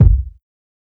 KICK_KNIGHT_IN_SHINING.wav